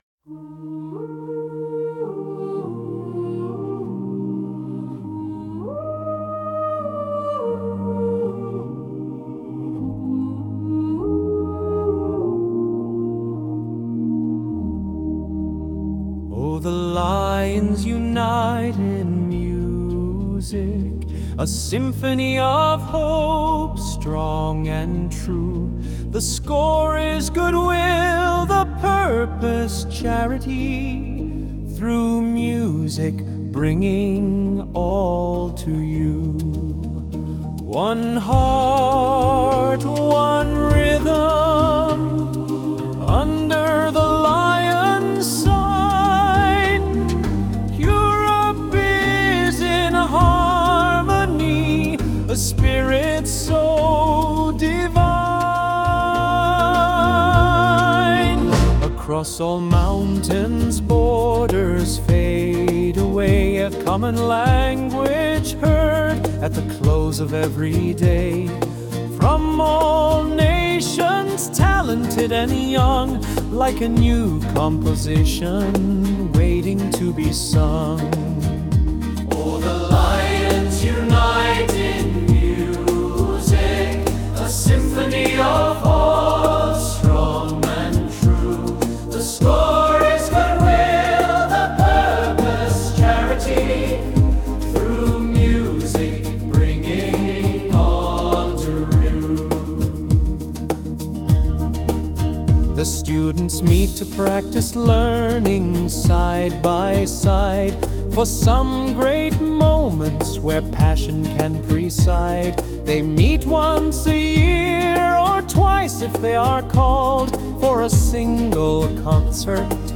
À l’occasion de l’Europa Forum à Dublin